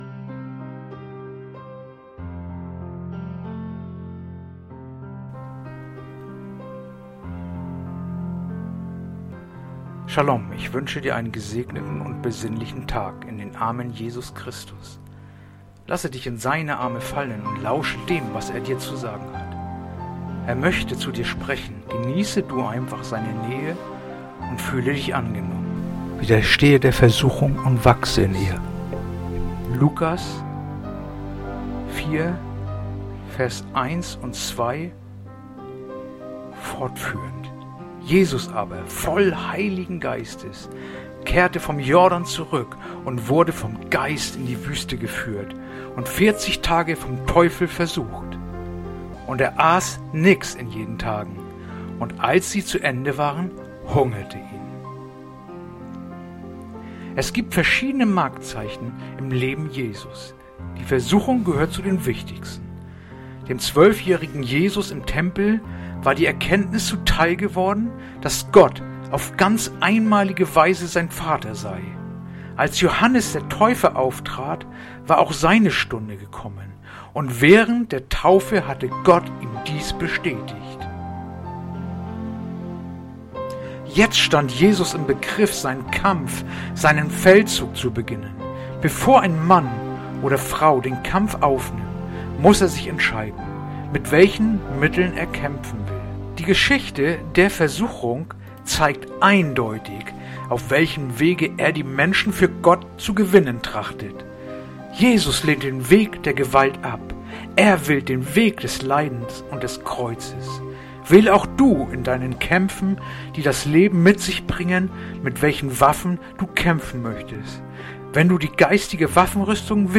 heutige akustische Andacht
Andacht-vom-21-Januar-Lukas-41.-2-ff.mp3